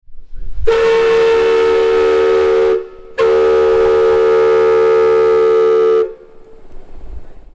Whistle-Mill-audio.mp3